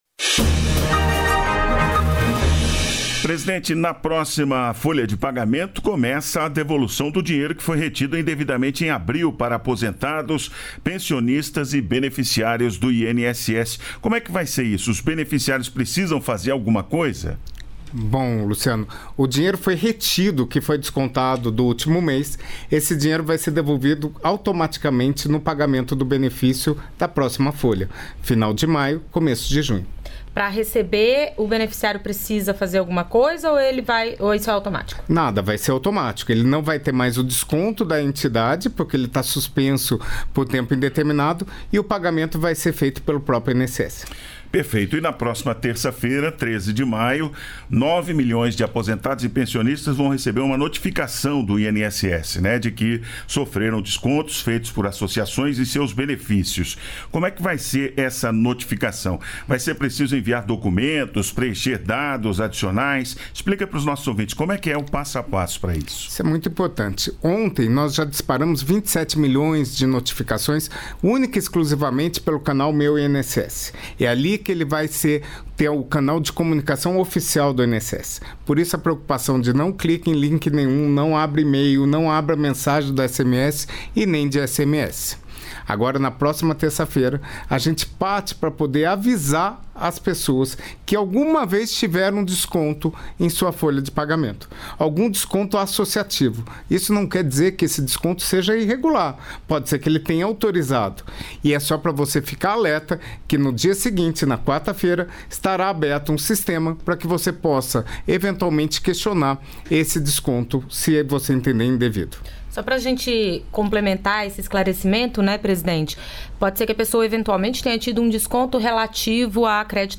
A nova linha de microcrédito para pequenos agricultores, que vai ser operacionalizada pela Caixa Econômica Federal, é tema da entrevista com o secretário Eduardo Tavares, do Ministério da Integração e do Desenvolvimento Regional.